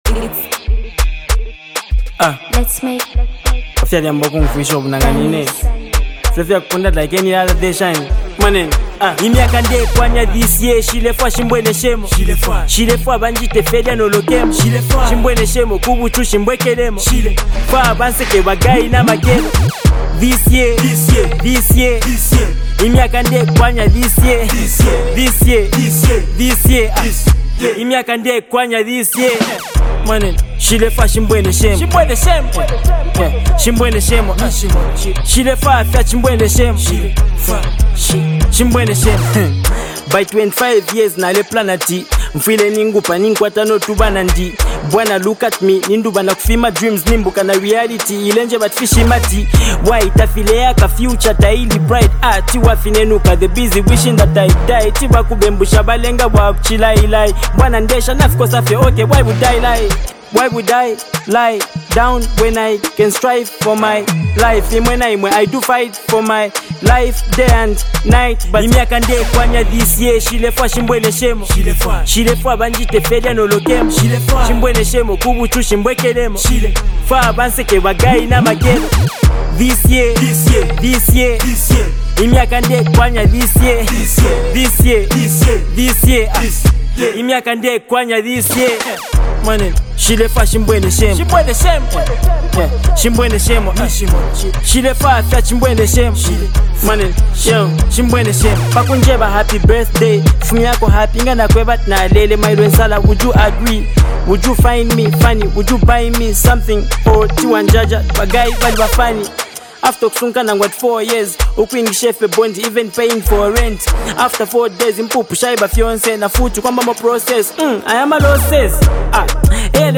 a deeply reflective and motivational track